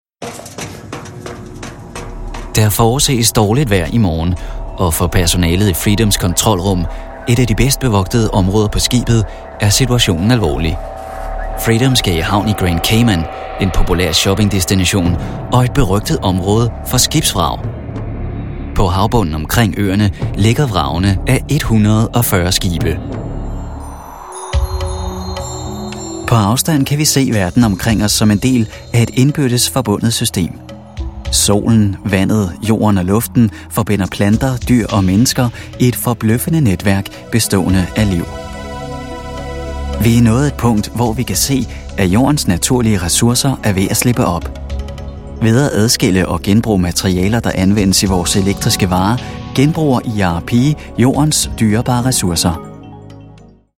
I specialize in the natural delivery, having a friendly and likeable voice the audience will feel comfortable with.
Sprechprobe: Sonstiges (Muttersprache):